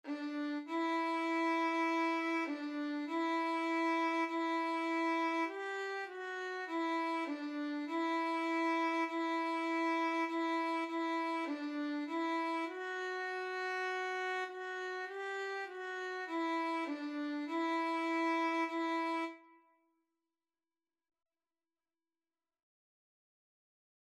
4/4 (View more 4/4 Music)
D5-G5
Violin  (View more Beginners Violin Music)
Classical (View more Classical Violin Music)